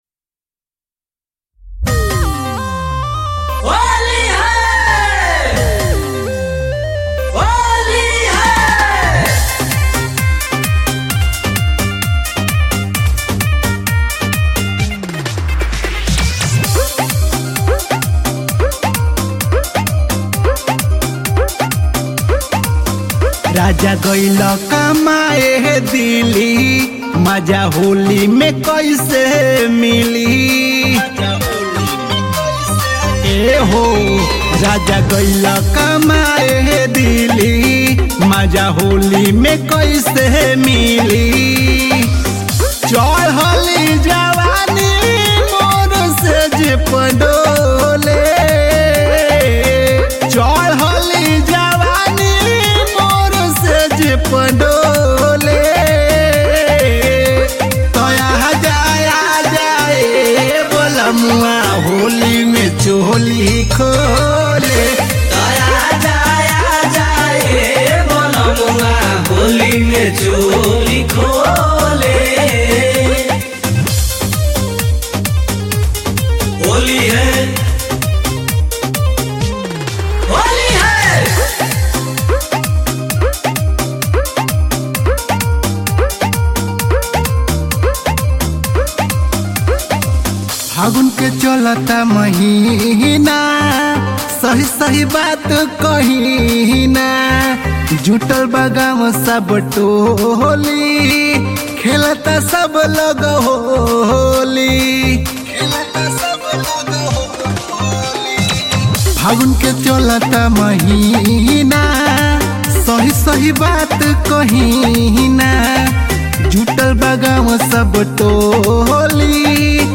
New Bhojpuri Holi Song